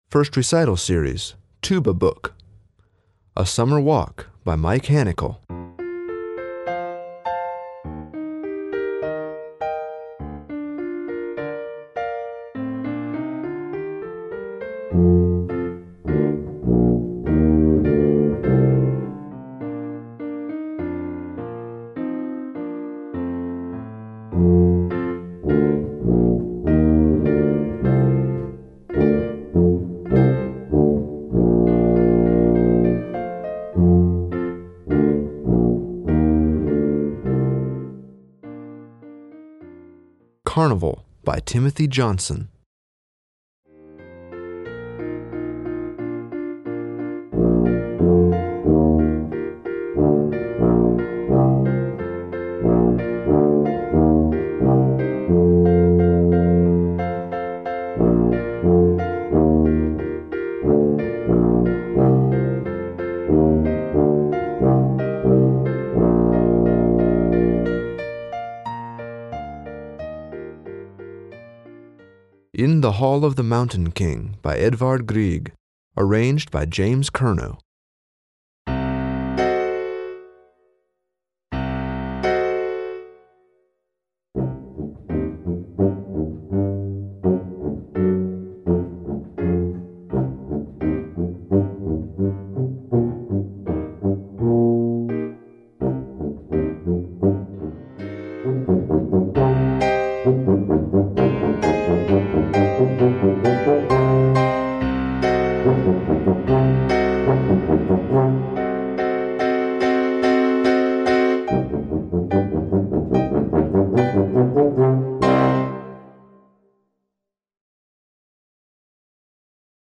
Voicing: Tuba Collection